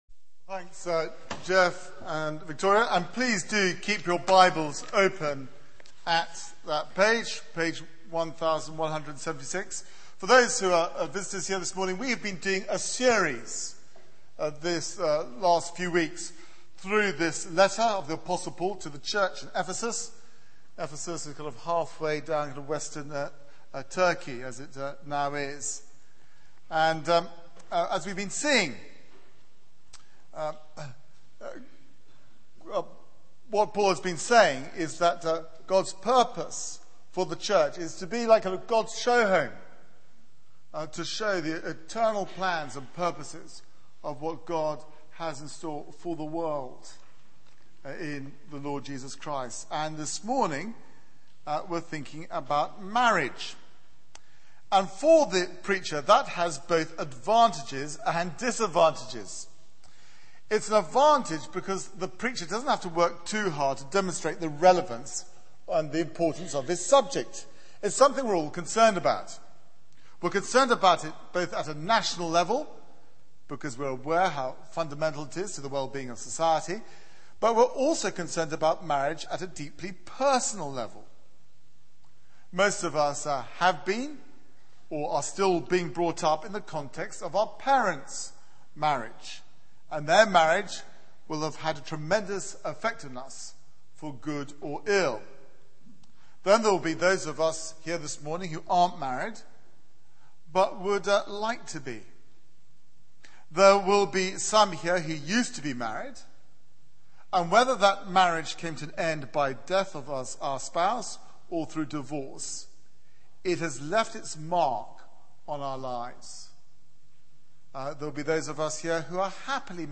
Media for 9:15am Service on Sun 11th Jul 2010 09:15 Speaker
Series: All Things Under Christ Theme: 'Husbands and Wives' Sermon